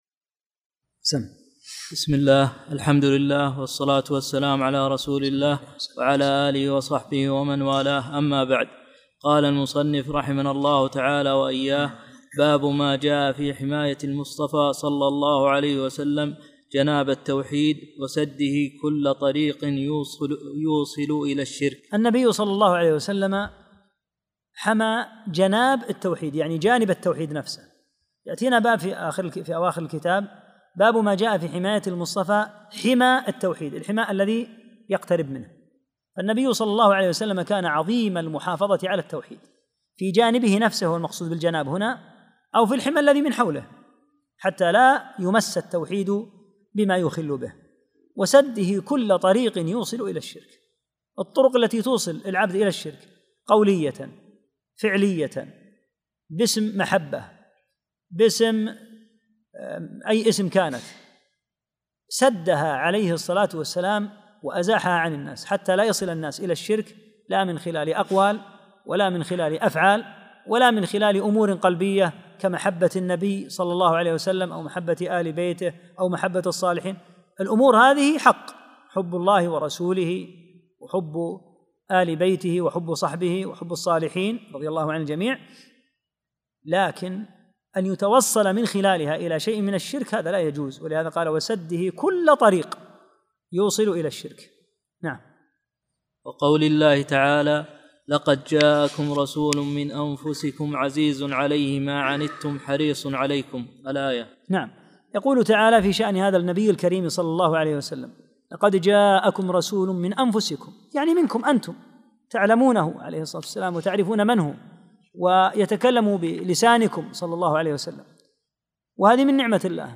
22- الدرس الثاني والعشرون